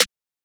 juugsnare2.wav